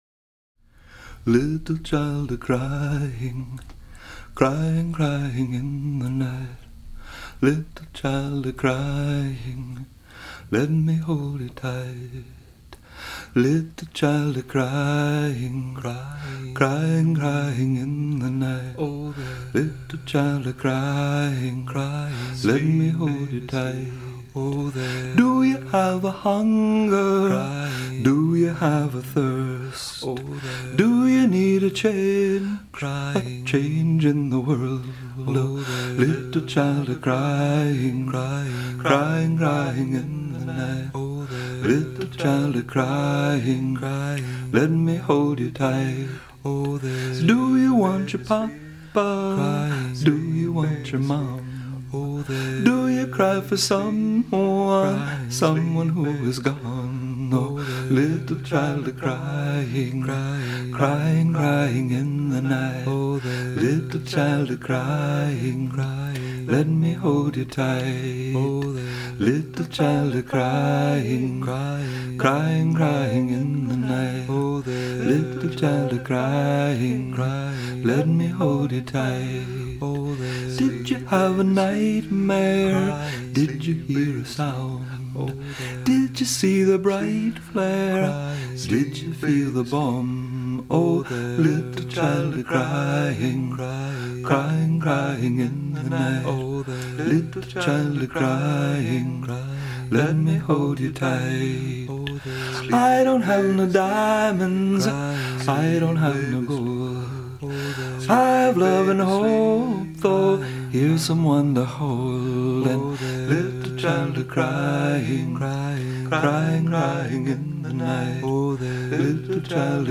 I recorded an album of lullabies this last summer.